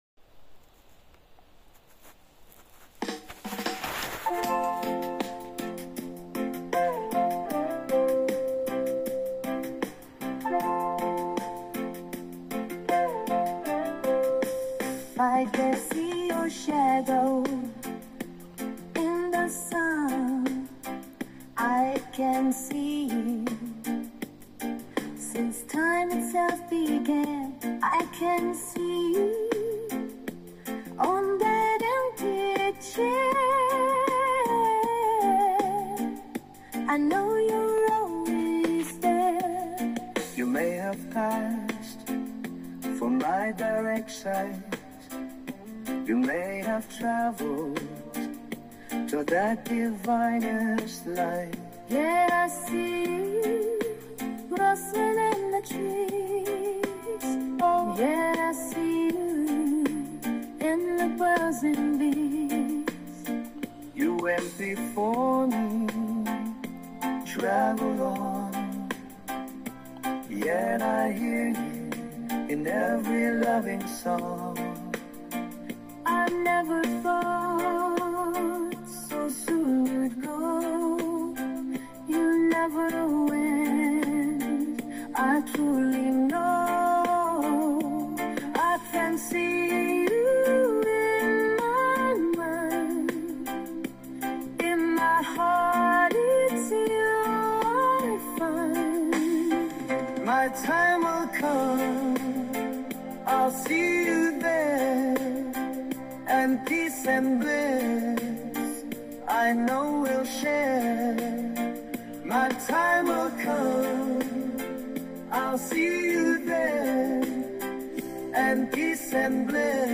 I CAN SEE YOU - vocals and music by AI